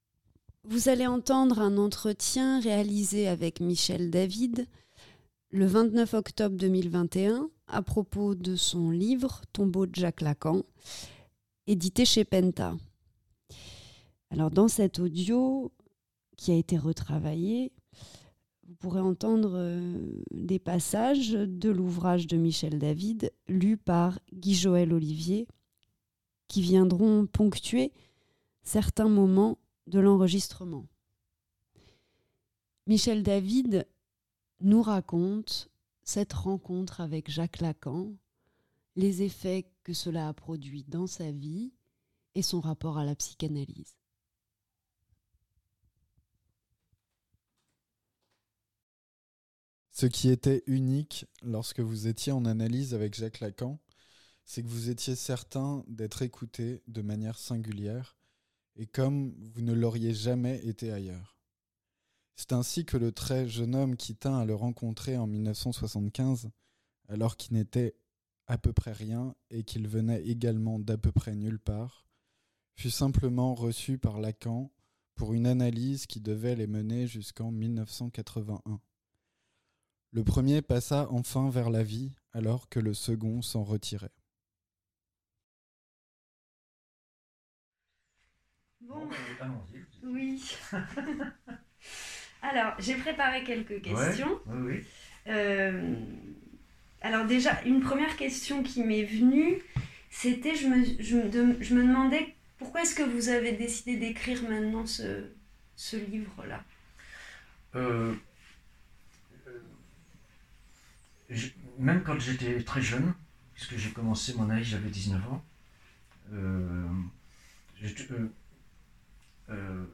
Entretien audio